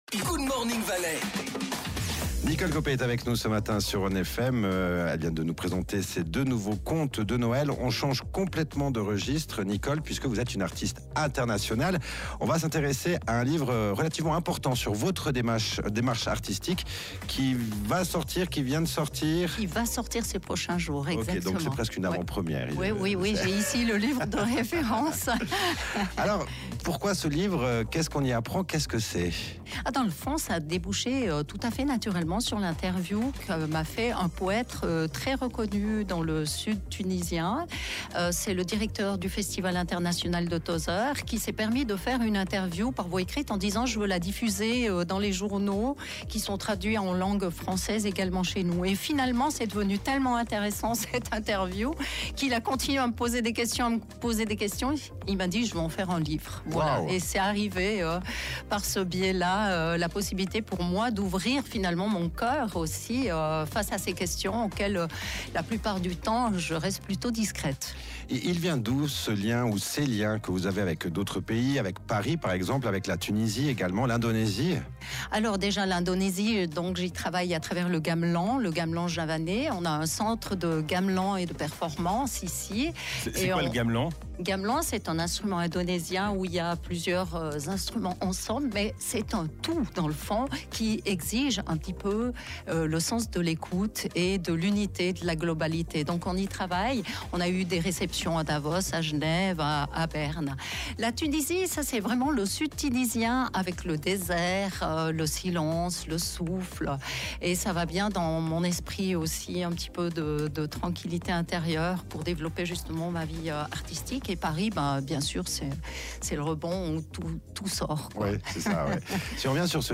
Ecouter l'émission